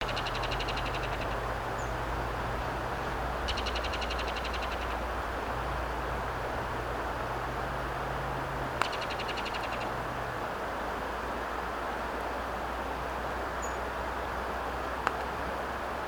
Ringed Kingfisher (Megaceryle torquata)
Sex: Male
Life Stage: Adult
Location or protected area: Ruta 82 (entre Villa Mascardi y Cerro Tronador)
Condition: Wild
Certainty: Observed, Recorded vocal